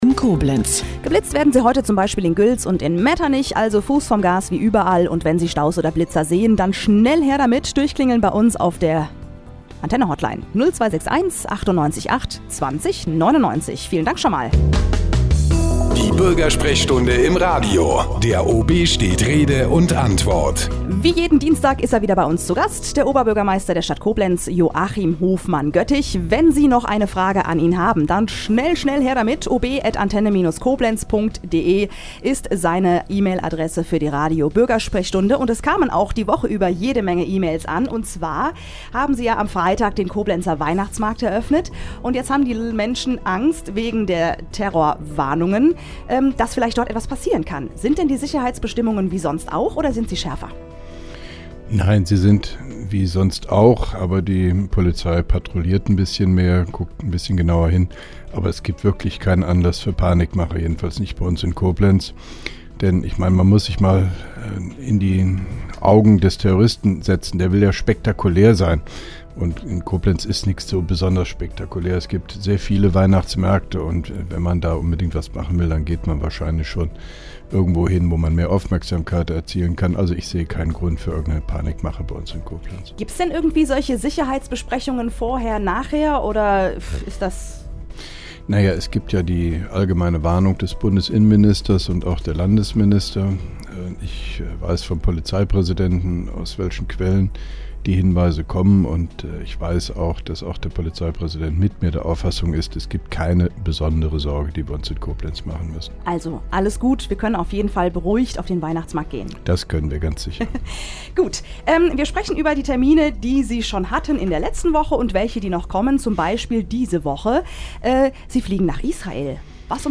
(2) Koblenzer OB Radio-Bürgersprechstunde 23.11.2010
Interviews/Gespräche